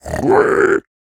Minecraft Version Minecraft Version snapshot Latest Release | Latest Snapshot snapshot / assets / minecraft / sounds / mob / piglin / retreat2.ogg Compare With Compare With Latest Release | Latest Snapshot